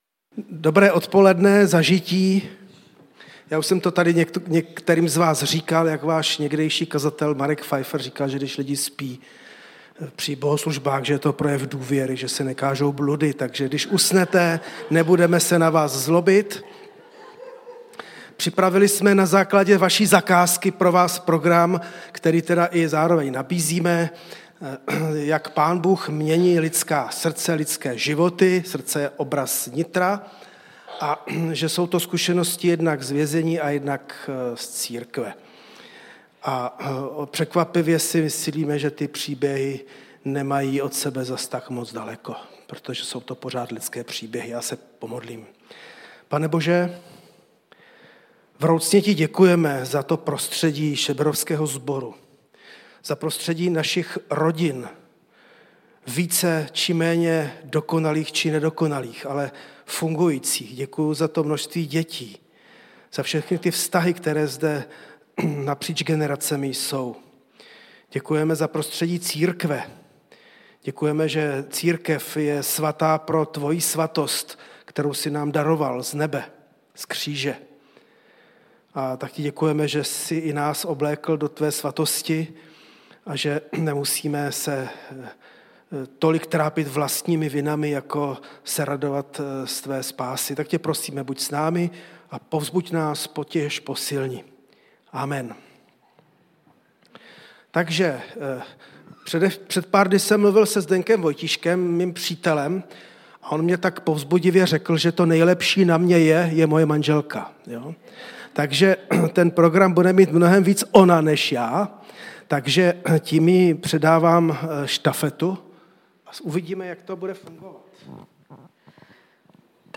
Kategorie: Seminář